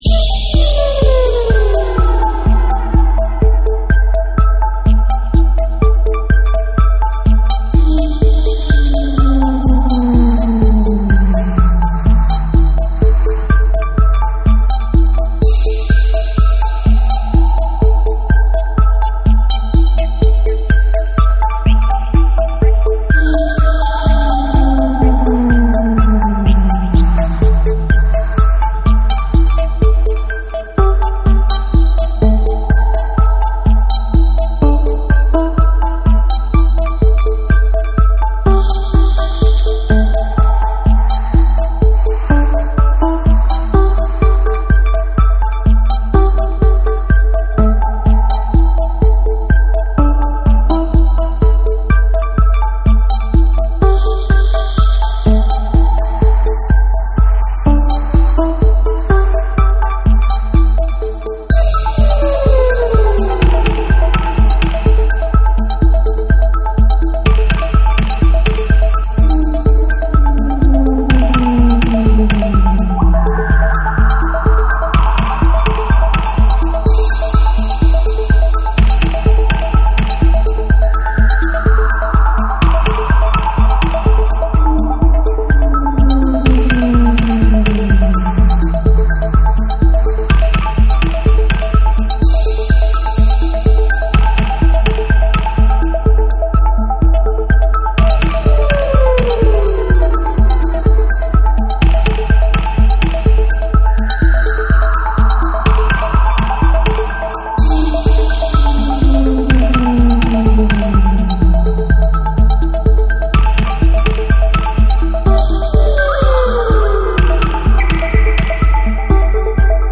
format : 12inch
TECHNO & HOUSE